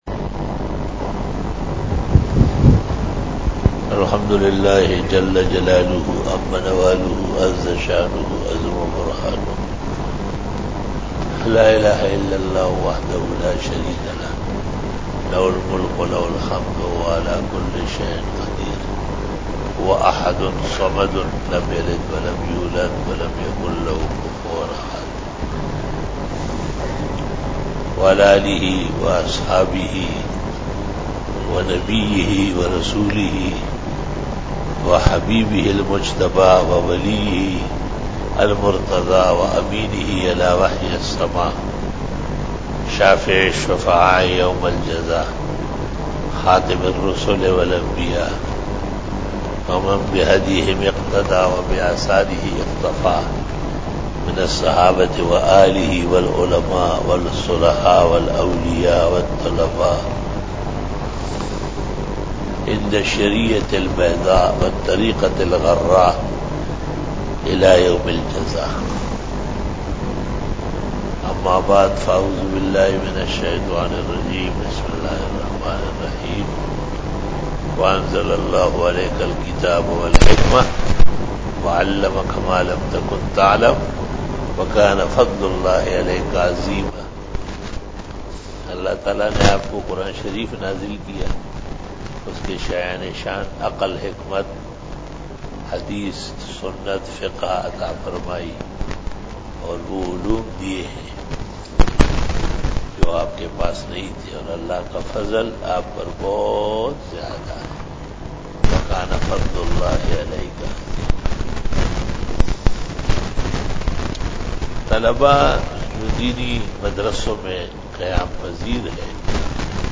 After Namaz Bayan